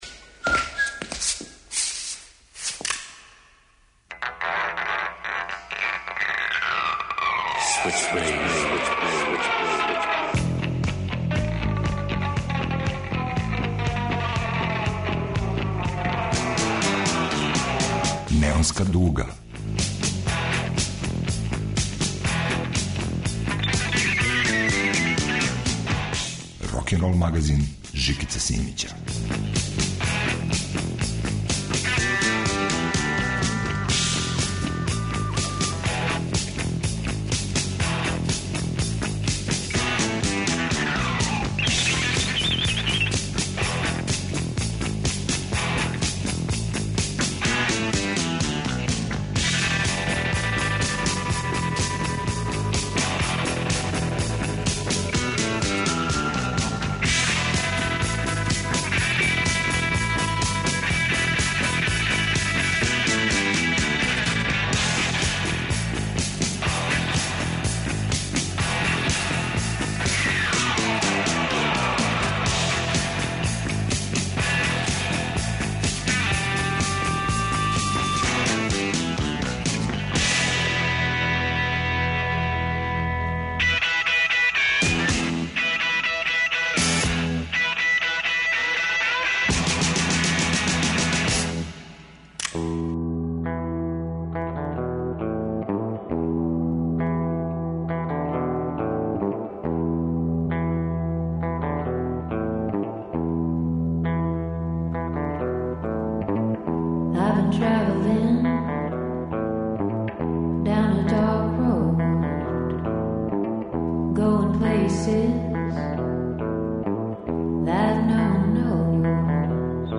Сем Пекинпо на белом бициклу лети осам миља високо. Поп психоделија и ноар фолк као звуци паралелне стварности.
Вратоломни сурф кроз време и жанрове.